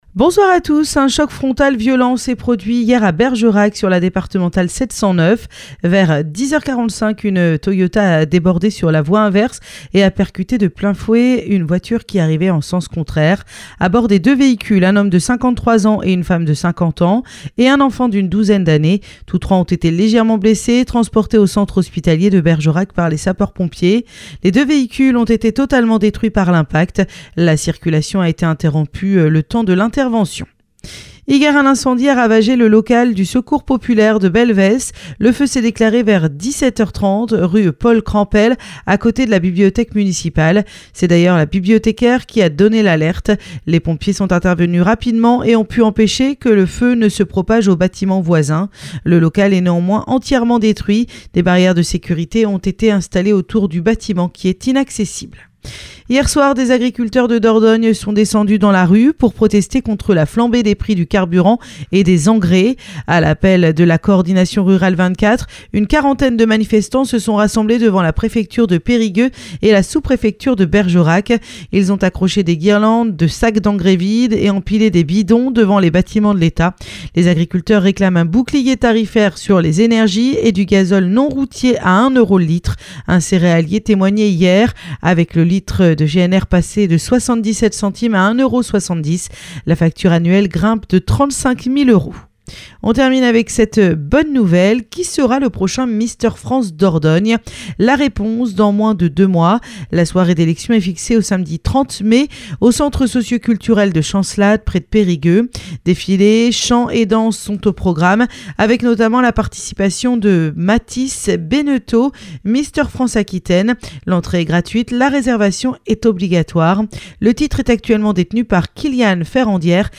Happy Radio : Réécoutez les flash infos et les différentes rubriques